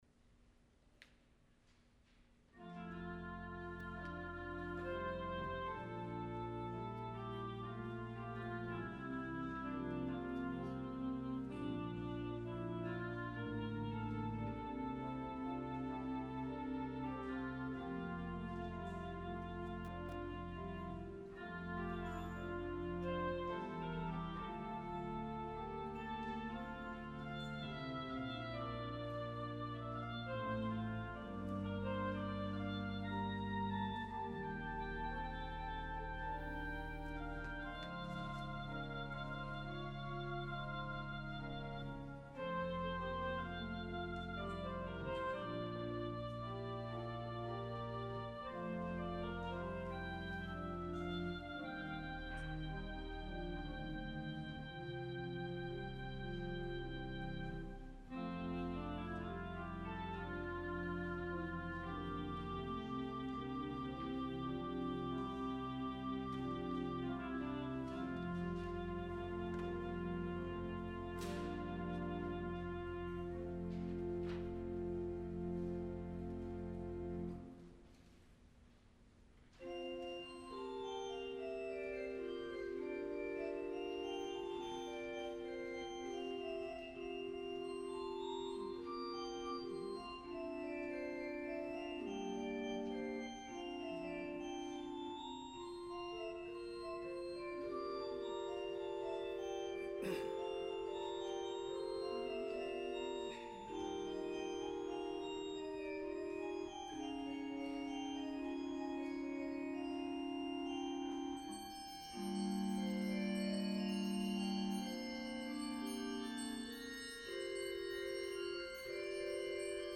Sunday Worship 6-13-21 (Third Sunday after Pentecost)